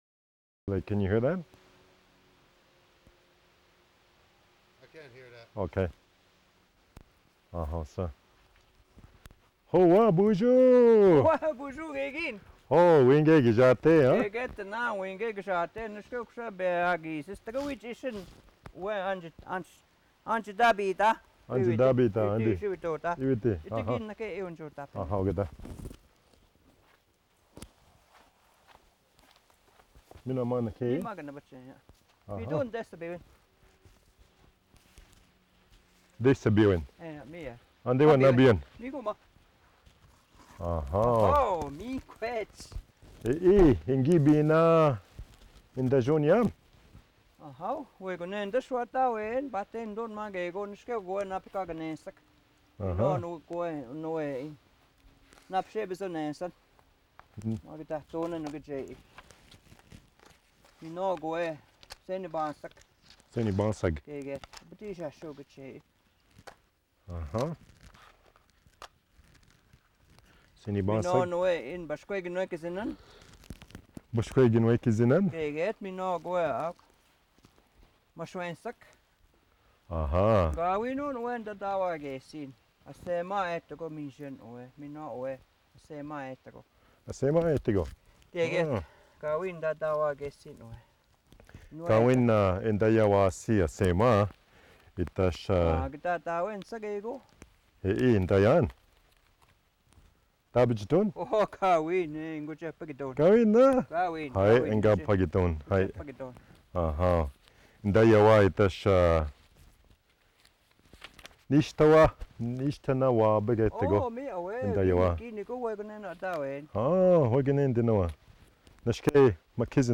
The two of them talk to come to an agreement on a price for a pair of moccasins.
Ojibwe Conversations